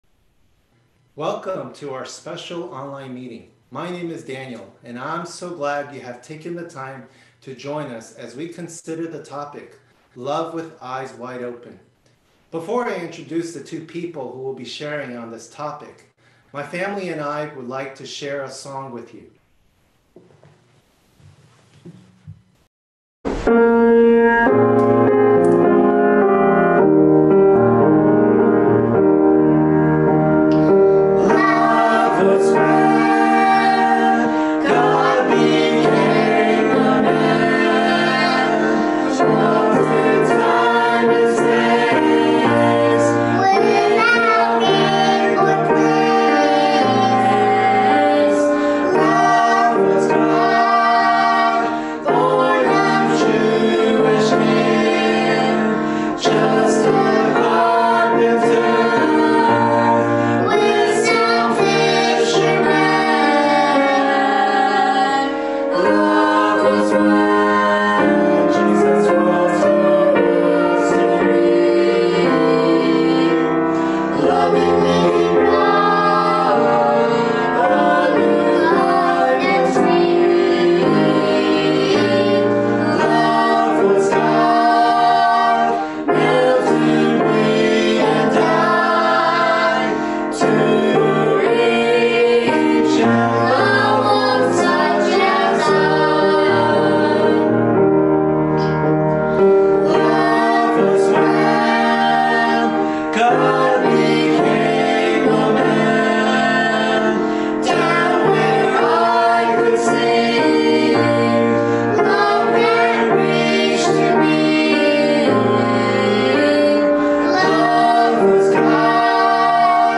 Gospel meeting at CBM 2020-12-19